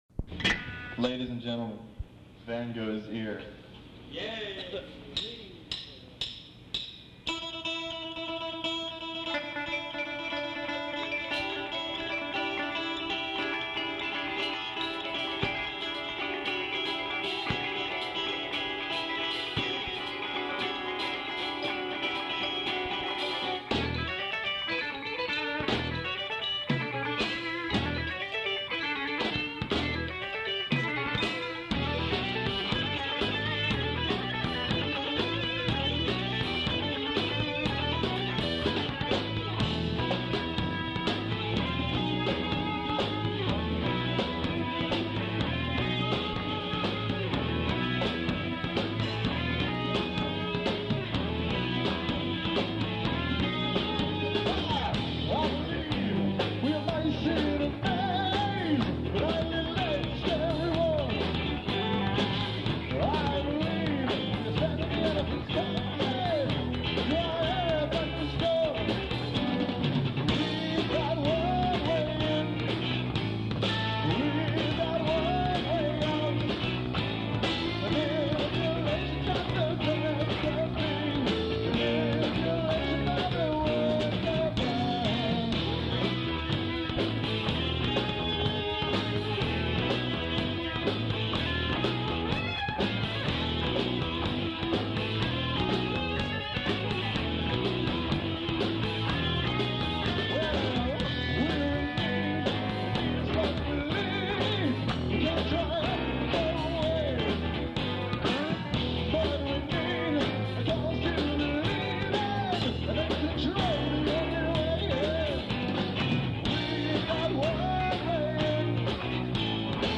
Electric Bassist in Los Angeles For Approximately Four Years
Van Gogh's Ear rehearsal before gig at Club 88, LA playing "One Way In, One Way Out"